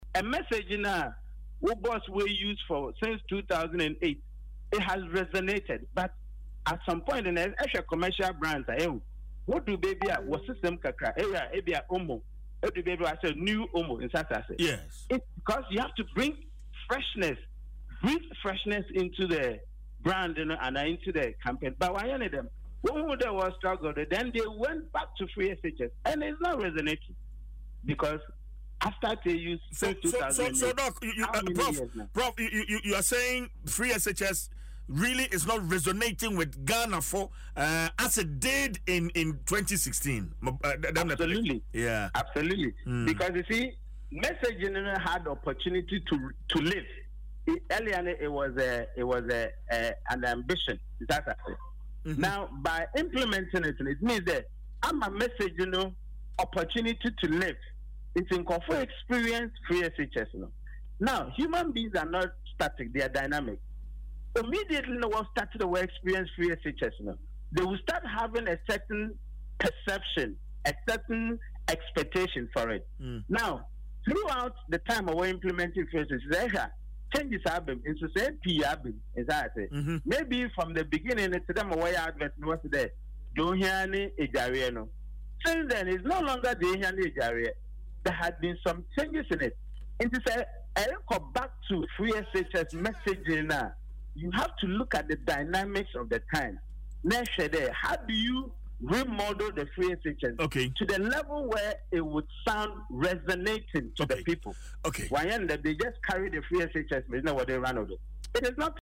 interview
Adom FM’s morning show